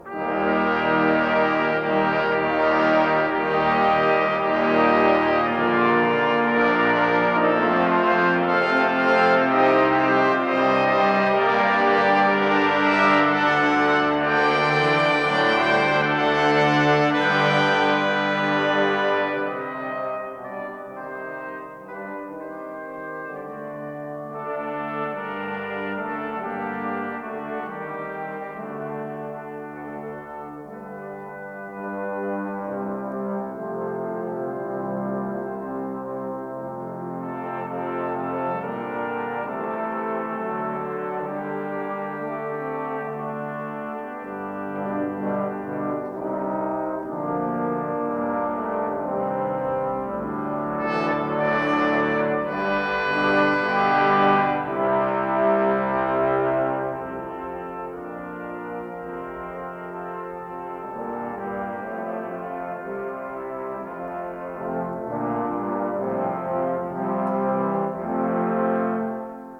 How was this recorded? A 1960 stereo recording